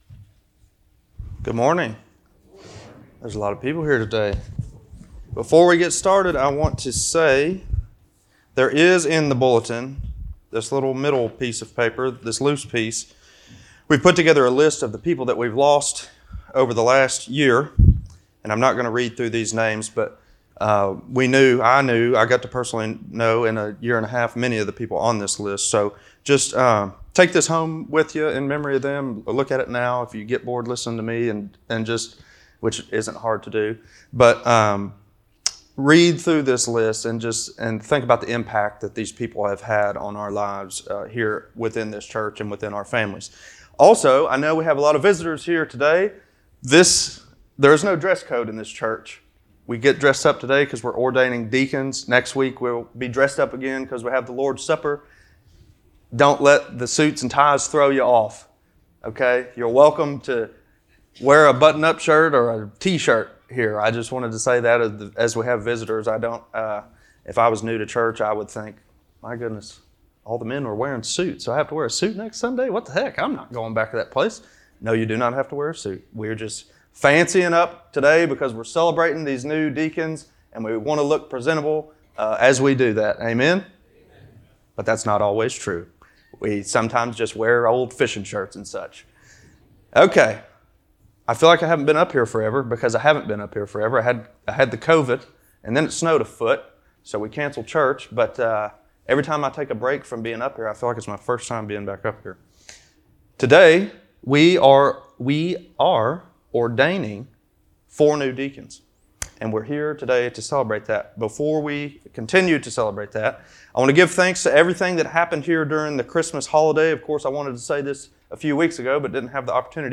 Deacon Ordination Service